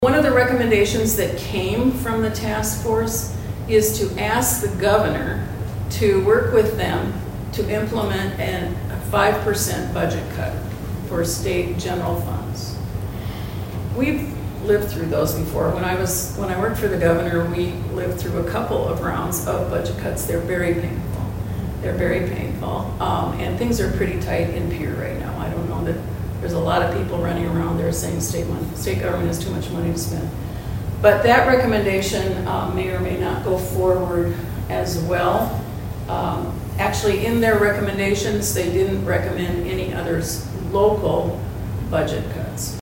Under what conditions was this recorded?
ABERDEEN, S.D.(HubCityRadio)- The Aberdeen Chamber of Commerce’s Chamber Connections Series continue Thursday at the K.O.Lee Public Library.